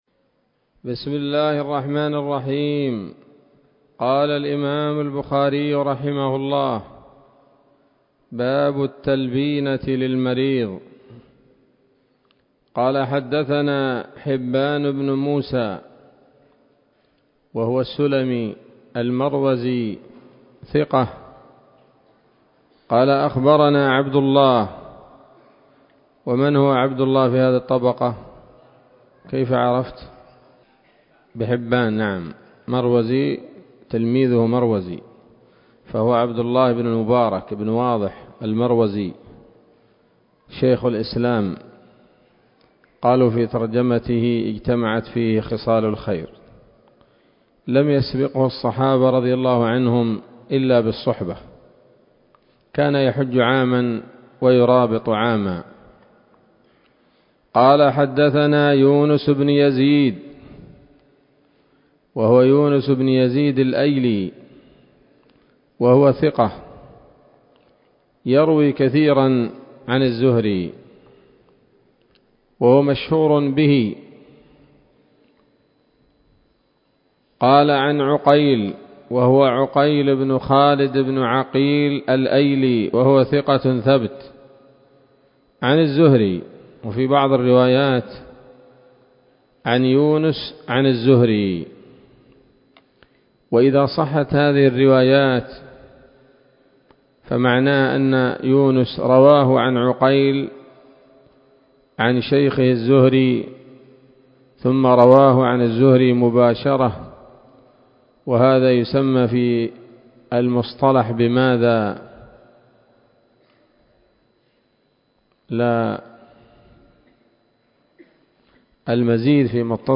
الدرس السابع من كتاب الطب من صحيح الإمام البخاري